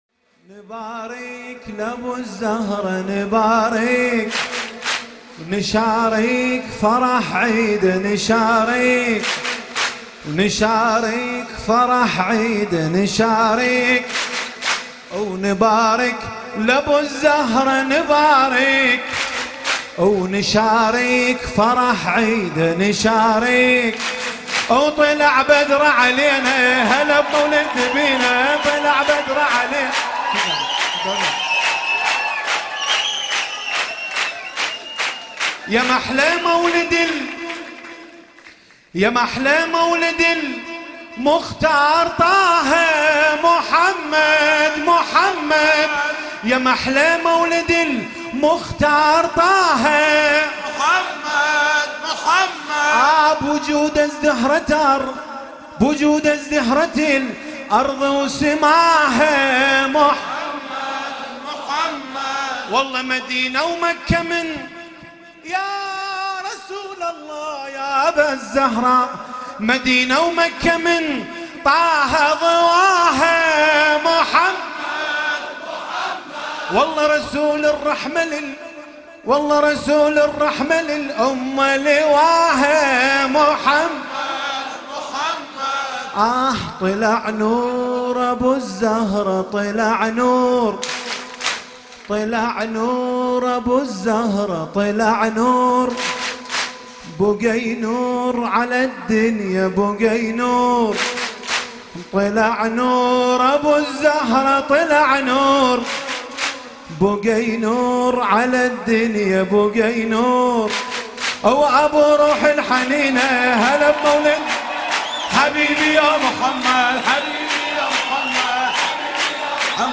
مولودی بسیار زیبا و شنیدنی از دو مداح خوش صدا